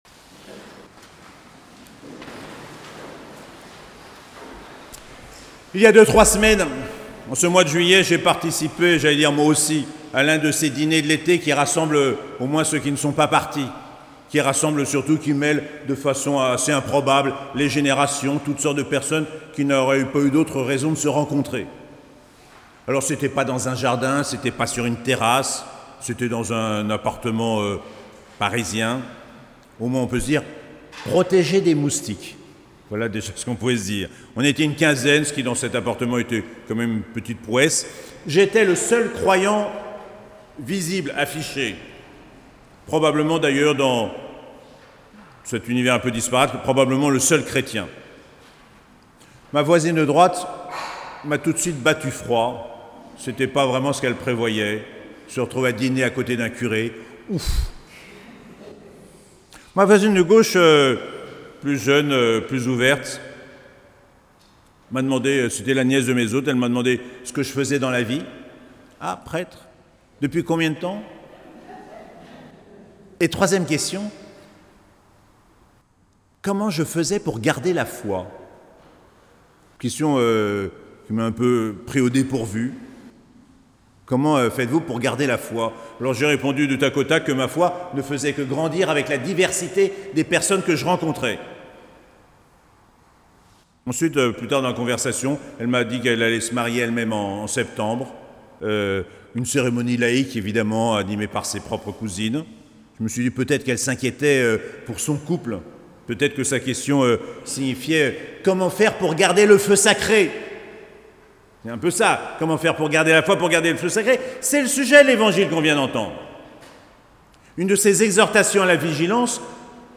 19ème dimanche du Temps Ordinaire - 7 août 2022